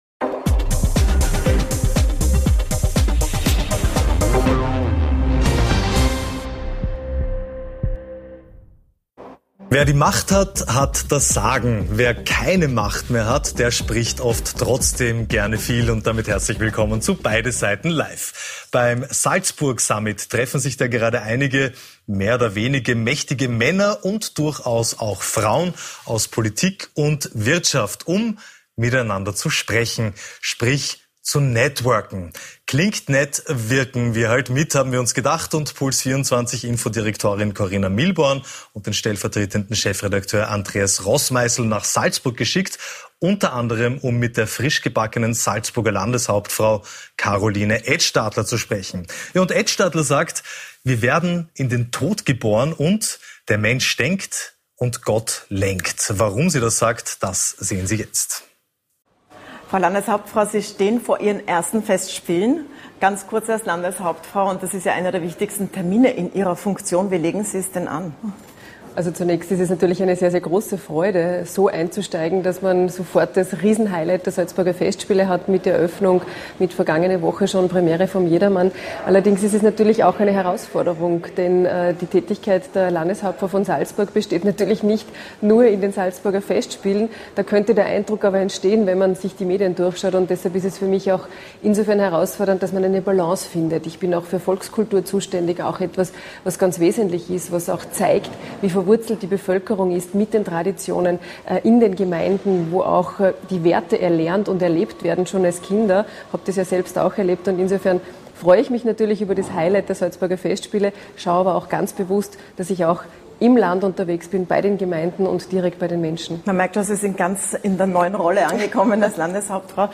Zu Gast: Ökonomin